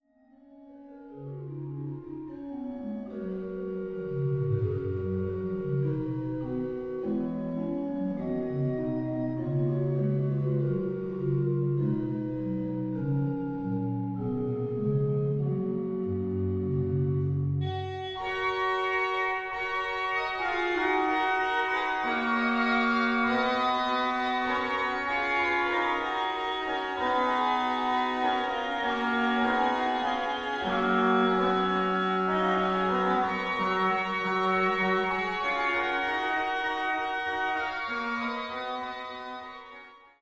orgue flamand (Cattiaux) de l’église de Beurnevésin